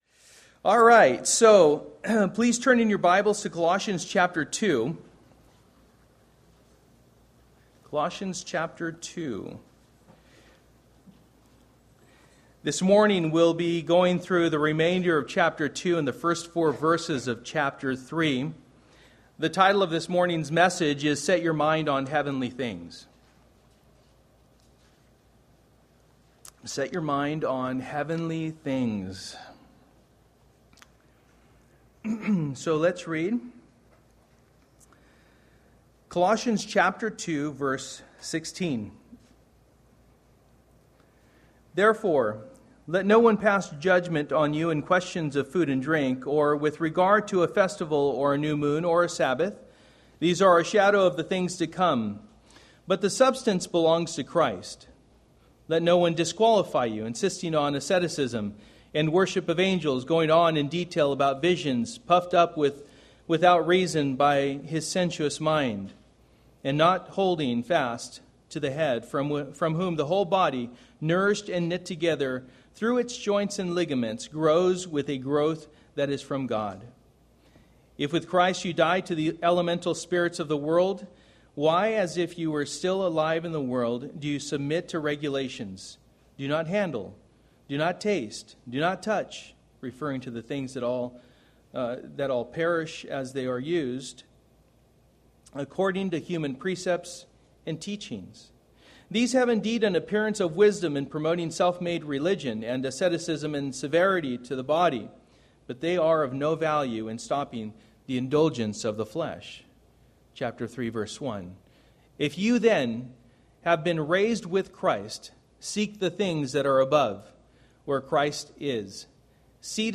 Service: Sunday Evening